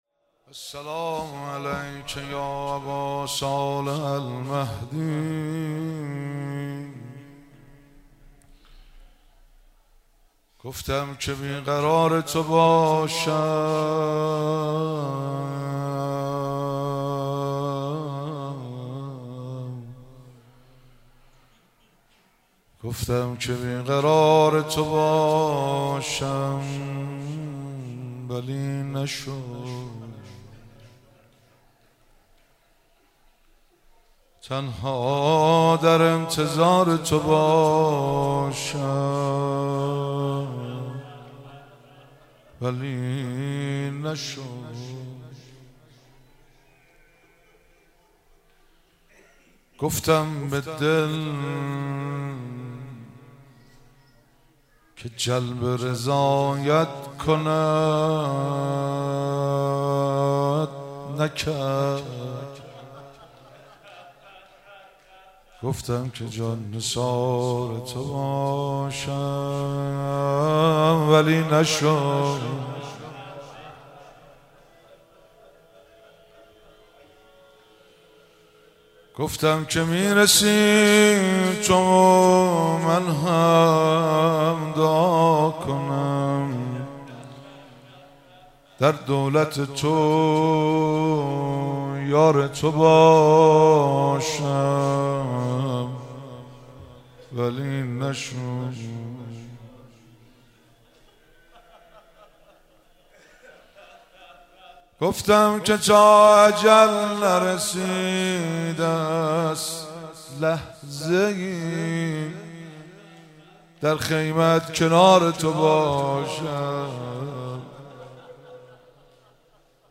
مراسم مناجات خوانی شب بیستم ماه رمضان 1444